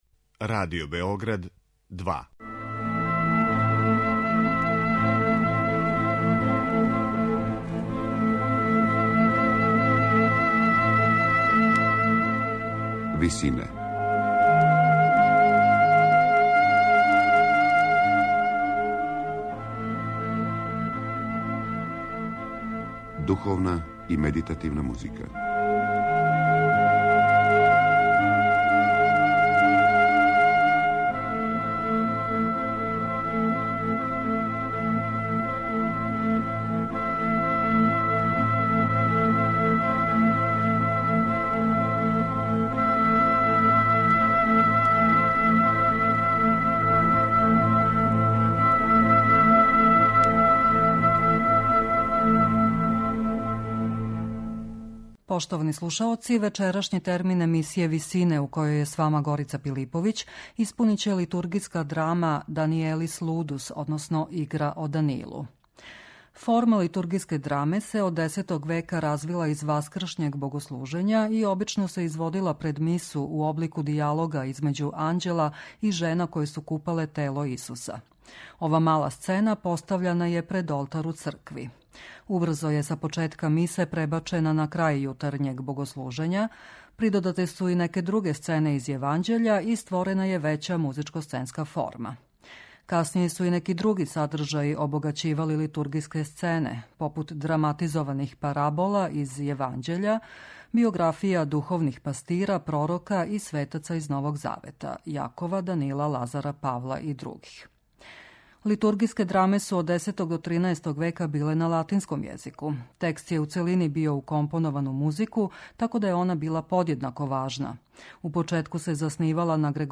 Литургијска драма